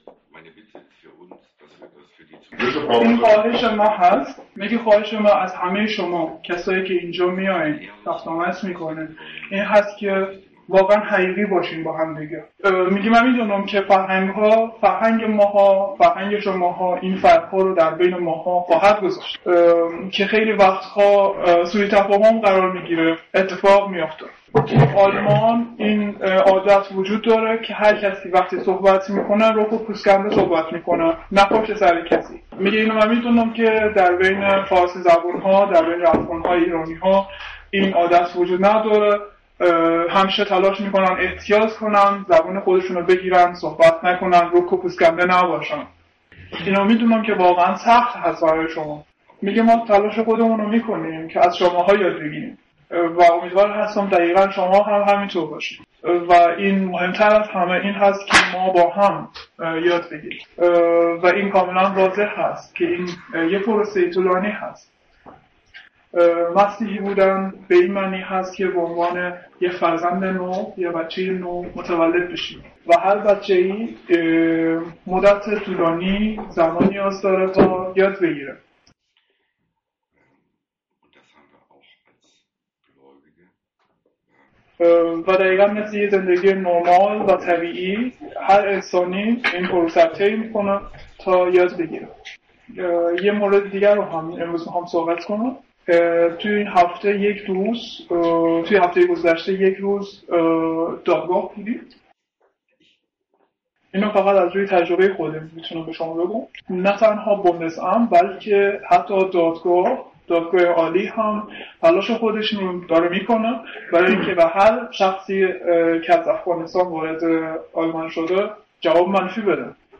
Übersetzung in Farsi